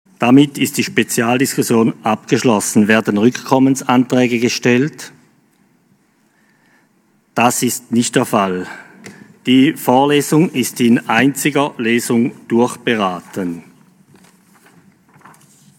Session des Kantonsrates vom 14. bis 17. September 2020